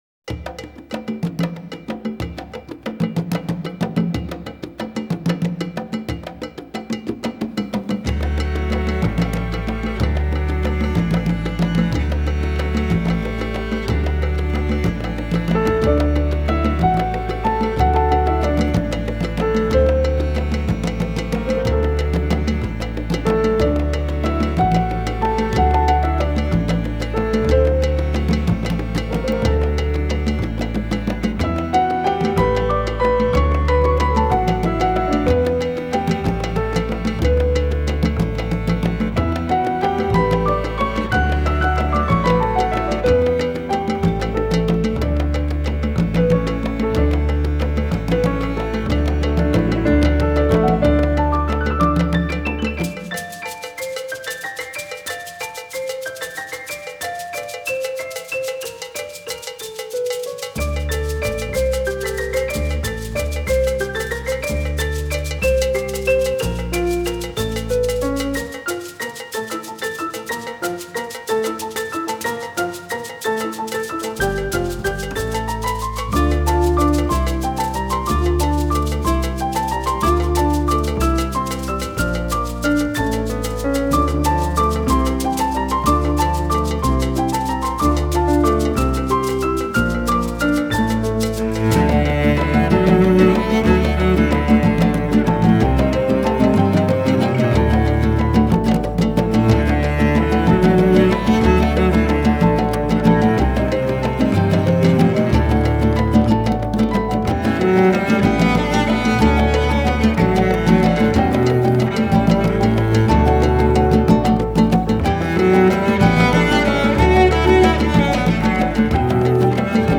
Genre: Jazz
熱情的恰恰，男聲也唱得非常乾脆瀟灑。
Recorded at Stiles Recording Studio in Portland, Oregon.